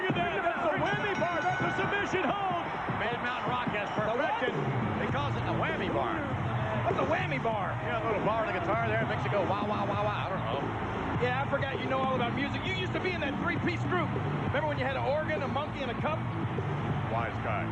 While I admire Vince for doing the sound effect to tell us what, precisely, a wammy bar is, I am sad that Gorilla didn’t make this call as well.
wammybar.mp3